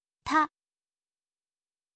ออกเสียง: ta, ทะ